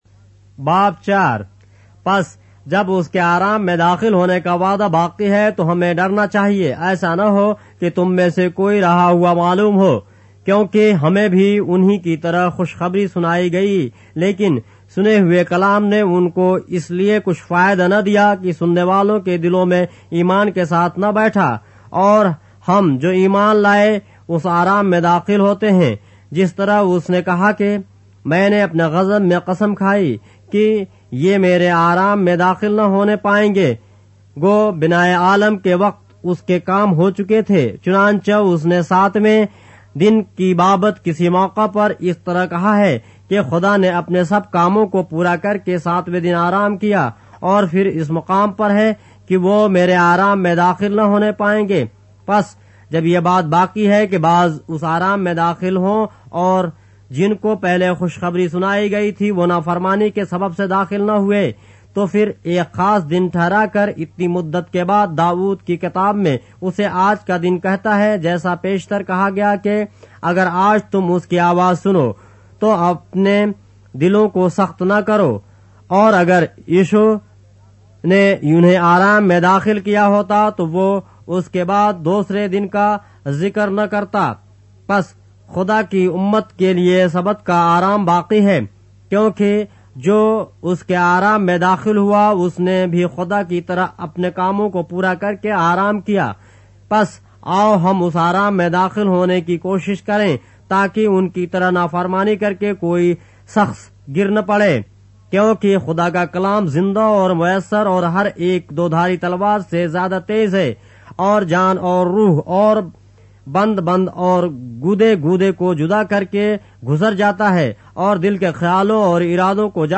اردو بائبل کے باب - آڈیو روایت کے ساتھ - Hebrews, chapter 4 of the Holy Bible in Urdu